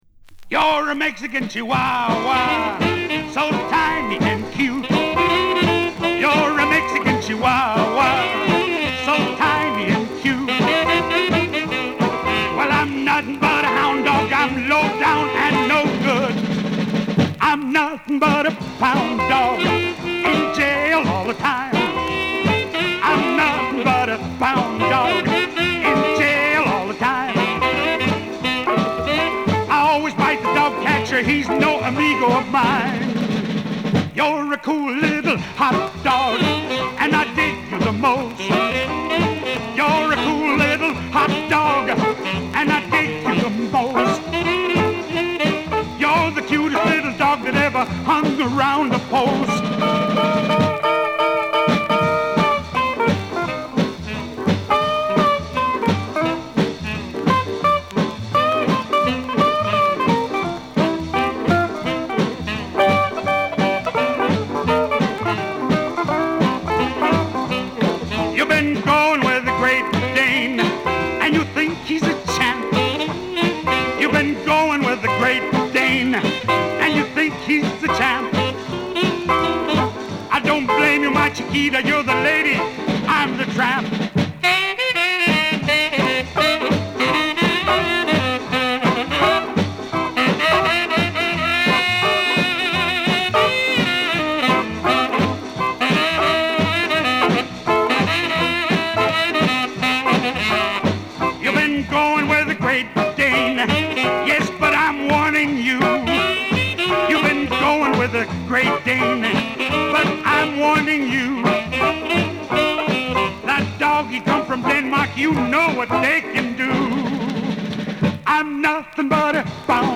ROCK'N'ROLL / OLDIES
コミカルなメキシカン・ヴァージョンが至福である。